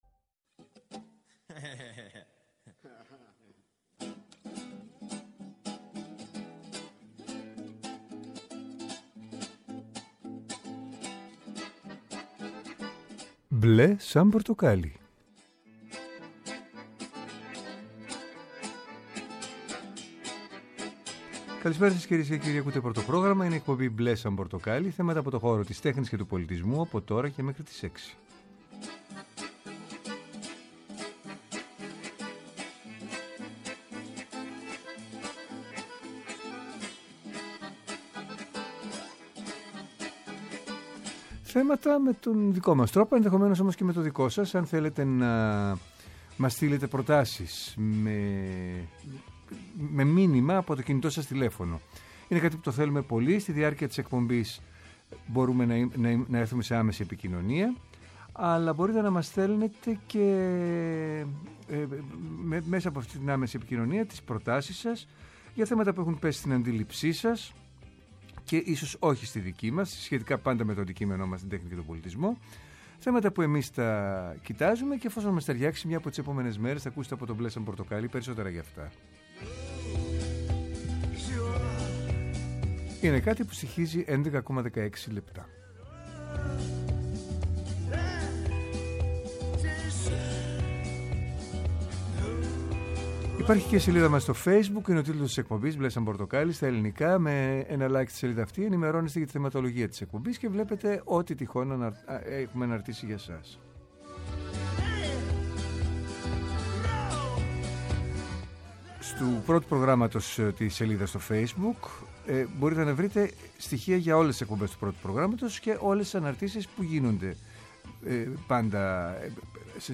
Μια εκπομπή με εκλεκτούς καλεσμένους, άποψη και επαφή με την επικαιρότητα.